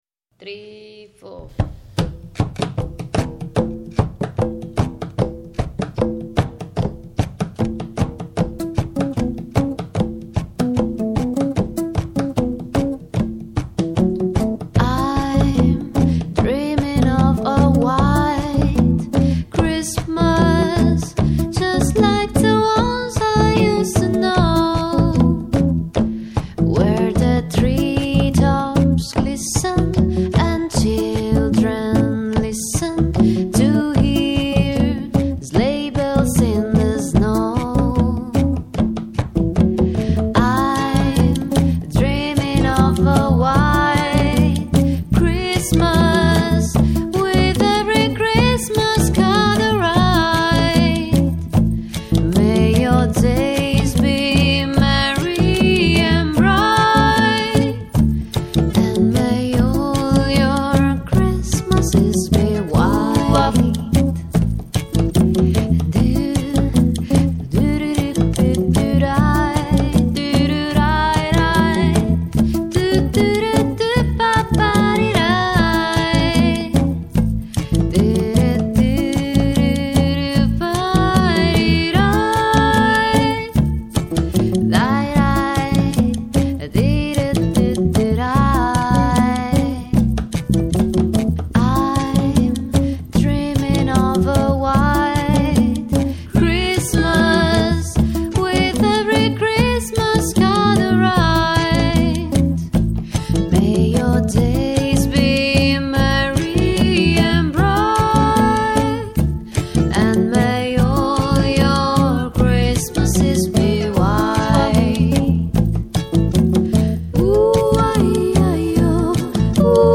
voce, basso
percussioni
chitarra, ukulele
Registrato al The Happy Home